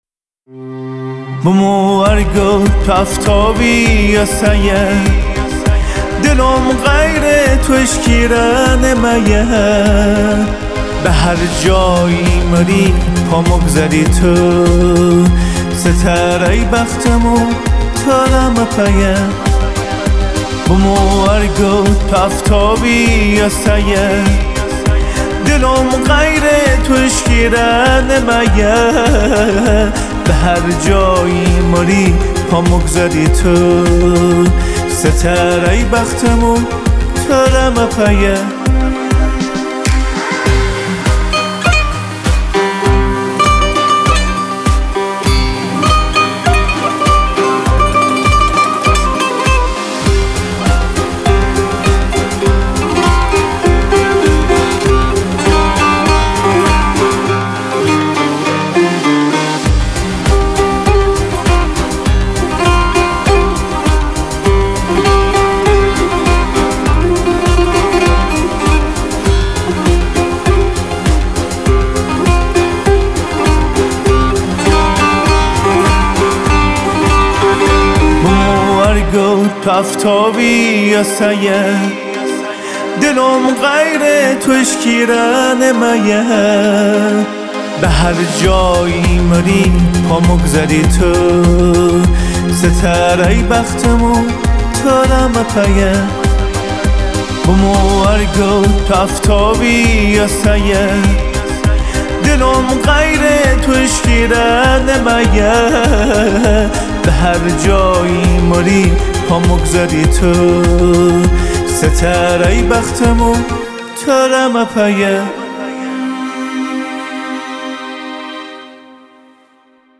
گویش نیشابوری